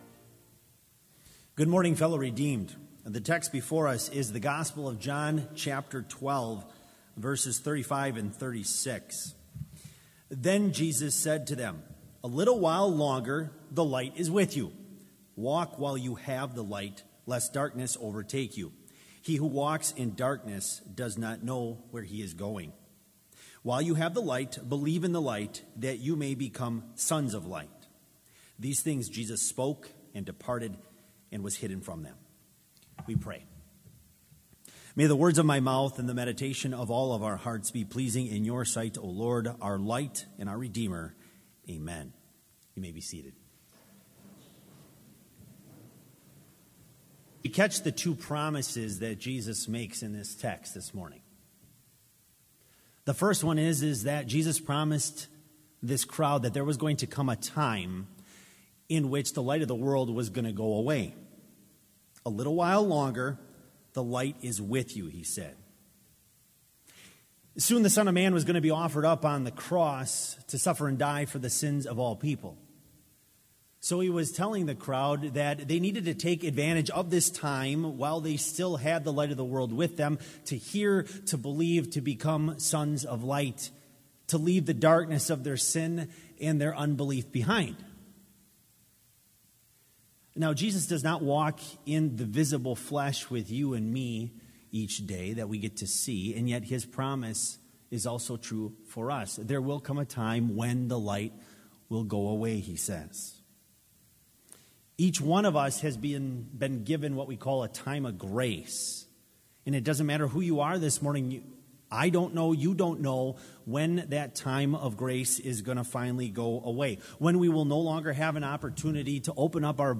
Complete Service
This Chapel Service was held in Trinity Chapel at Bethany Lutheran College on Thursday, February 28, 2019, at 10 a.m. Page and hymn numbers are from the Evangelical Lutheran Hymnary.